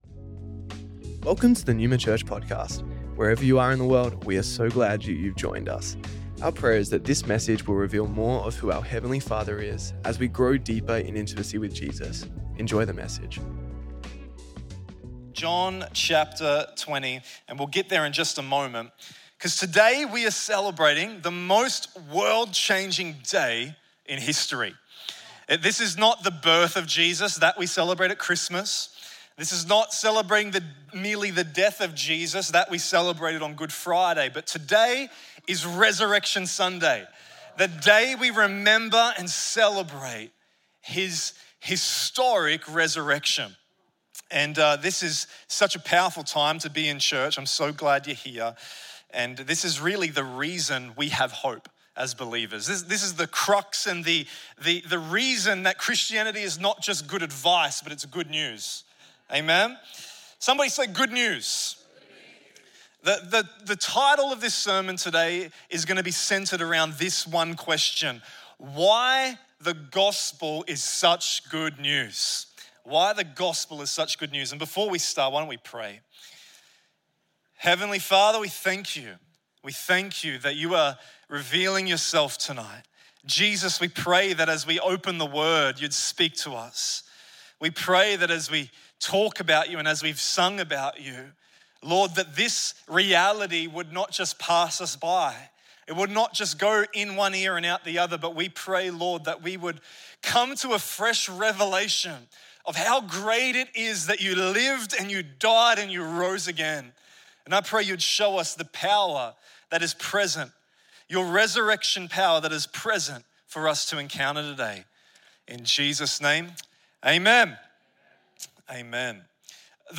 Resurrection Sunday Originially recorded at Neuma Melbourne City on April 5th, 2026